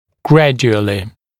[‘grædjuəlɪ] [-ʤu-][‘грэдйуэли], [-джу-]постепенно